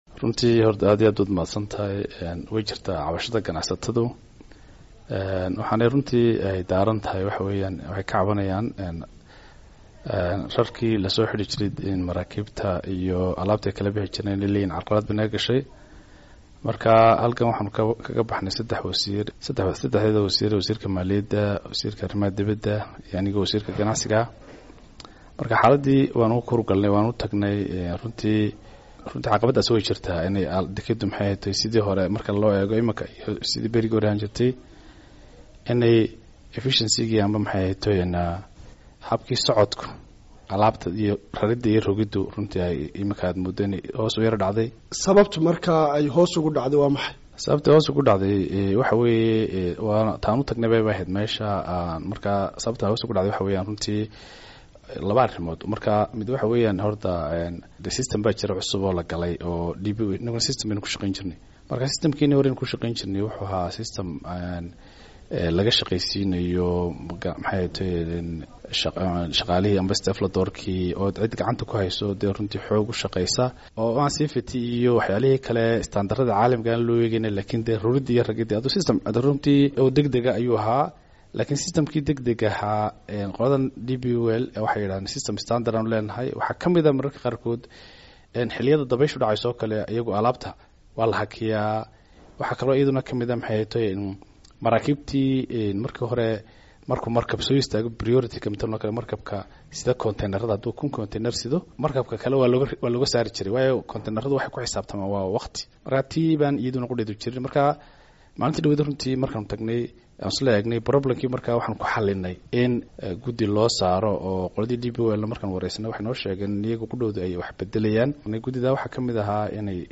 Wareysi: Wasiirka ganacsiga Somaliland